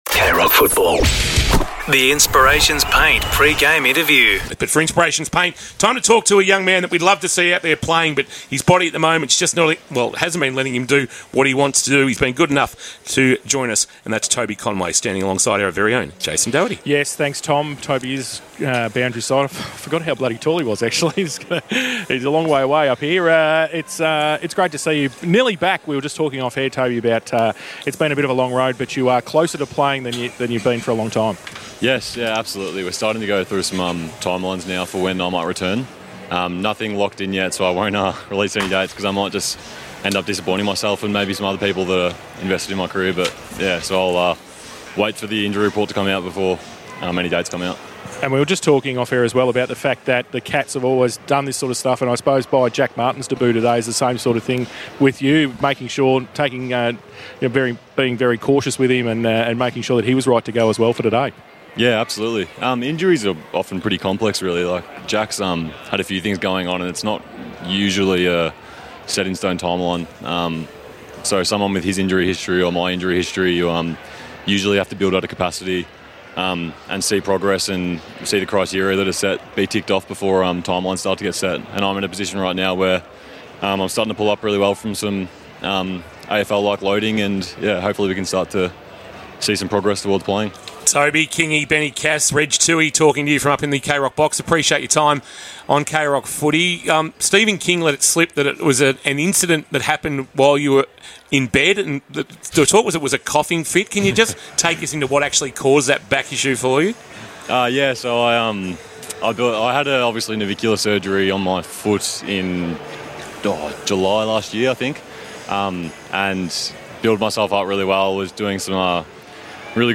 2025 - AFL - Round 13 - Geelong vs. Gold Coast: Pre-match interview